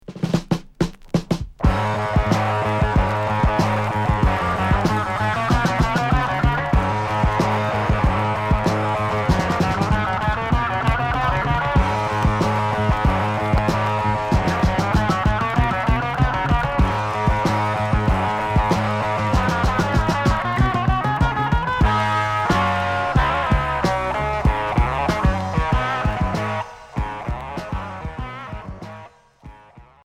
Progressif Unique 45t retour à l'accueil